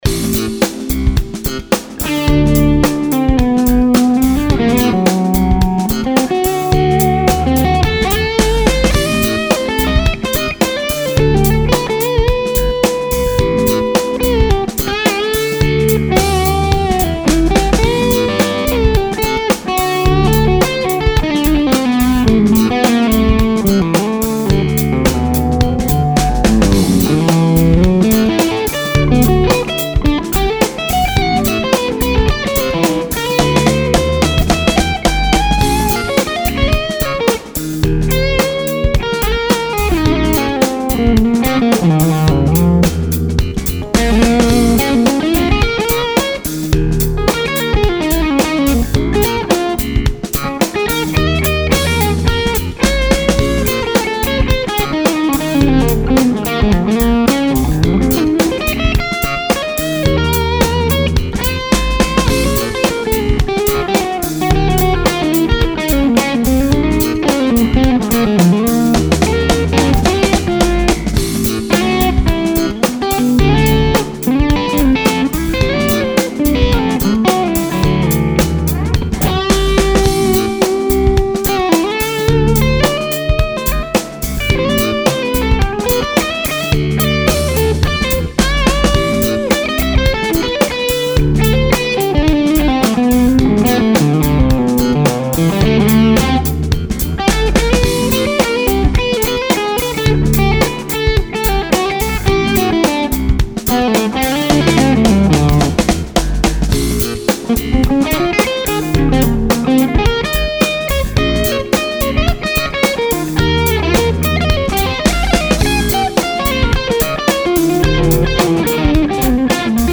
I put back the 47pf on the preod network. Nice difference!!
I have heard a lot of your clips and and these to me sound like the highs, the very top of your spectrum, sounds a little fizzy instead of crisp and glassy like ueasual. bass on clip 4 is the best IMHO.